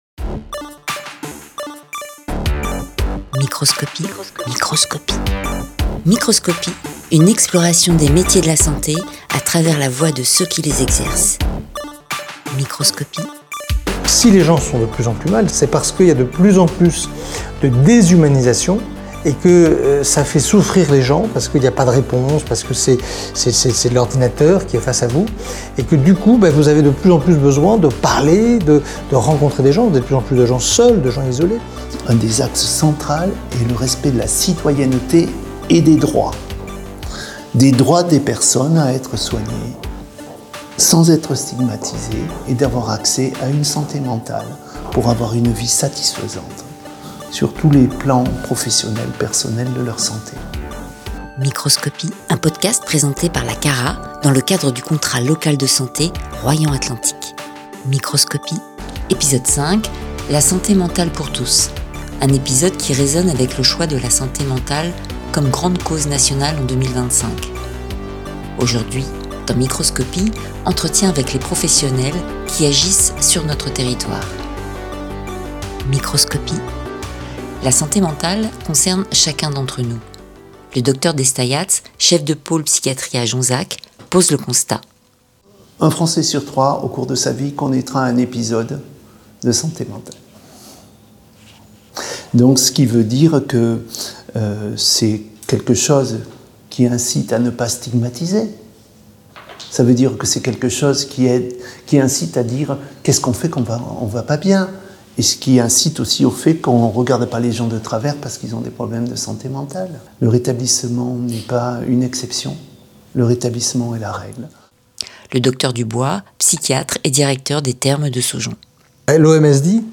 À travers des témoignages sincères de professionnels en poste ou en formation sur le territoire de Royan Atlantique, partez à la découverte d’un univers aussi passionnant que méconnu.